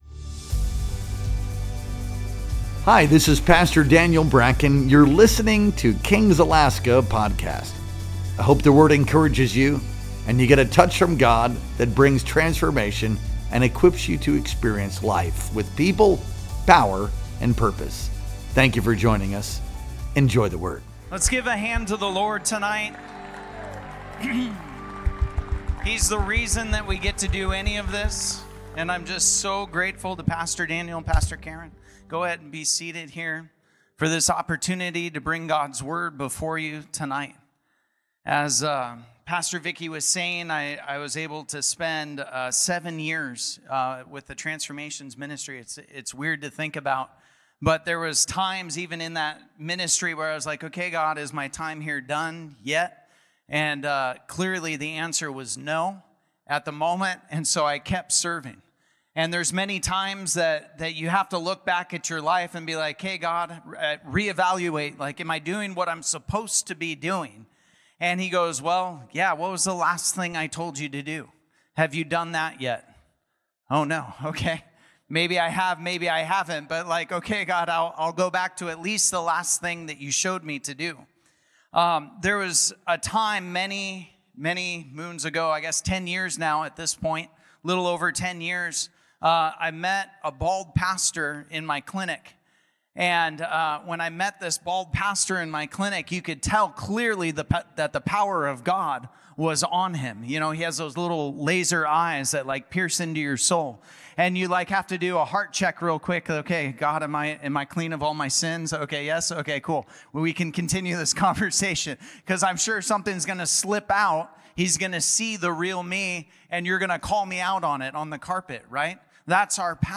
Our Sunday Night Worship Experience streamed live on November 17th, 2025.